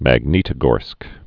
(măg-nētə-gôrsk, məg-nyĭ-tə-gôrsk)